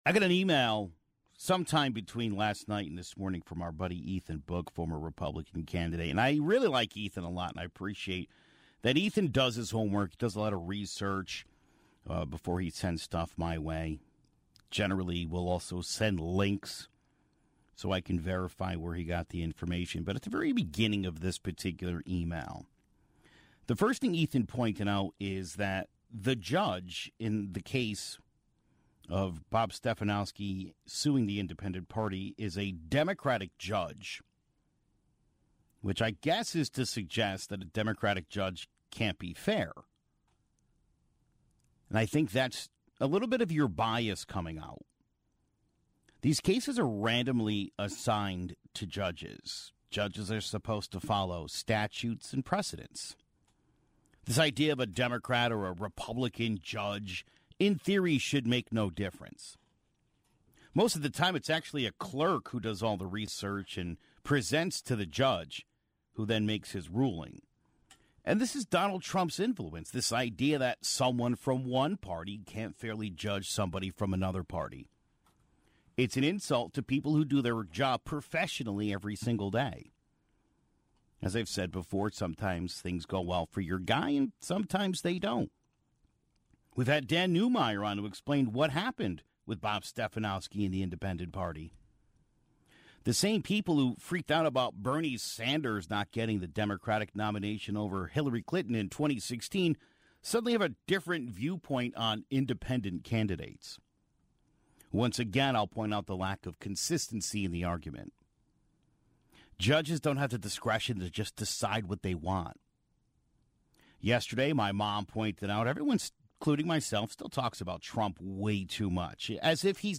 After that, Connecticut Lieutenant Governor Susan Bysiewicz joined the conversation to talk about her and Governor Ned Lamont's campaign (42:22).